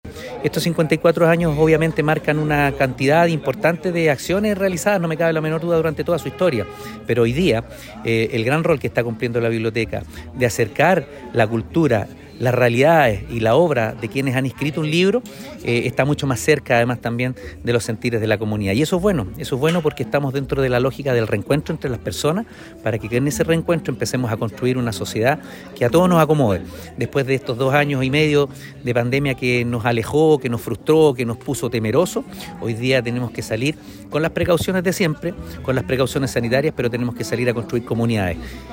Hoy el rol que está cumpliendo la Biblioteca es el de acercar la cultura más a las personas, esto mediante visitas a barrios, colegios, entre otras instancias. Algo que se necesita ya que después de dos años de pandemia a la gente le hace falta ese reencuentro”, comentó Oscar Calderón Sánchez, alcalde de Quillota, durante la ceremonia.
Cuna-alcalde-Ceremonia-aniversario-Biblioteca.mp3